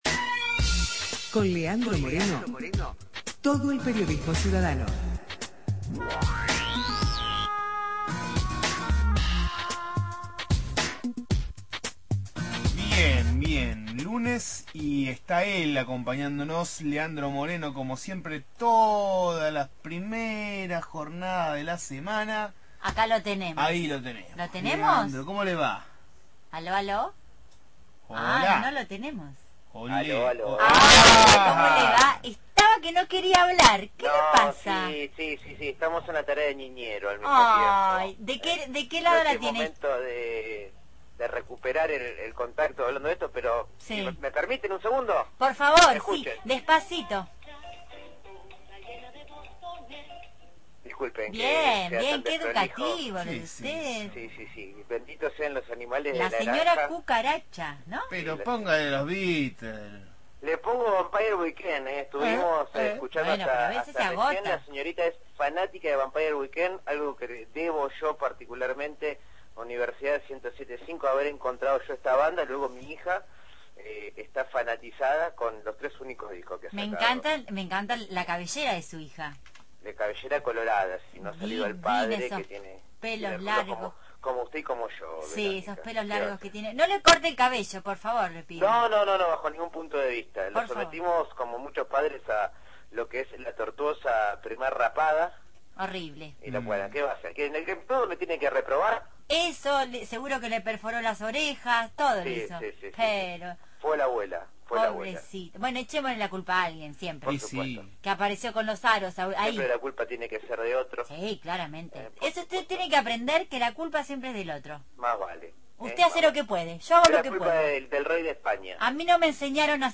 columna de Periodismo Ciudadano